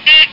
Digital Beep Sound Effect
Download a high-quality digital beep sound effect.
digital-beep.mp3